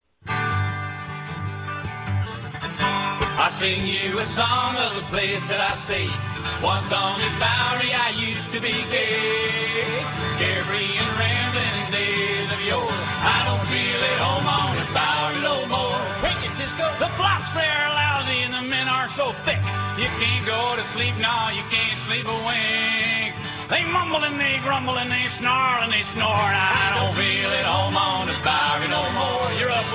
Order original cast recording right from this site: